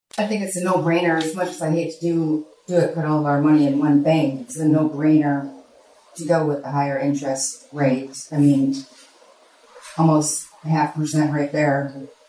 Council member Angela Evans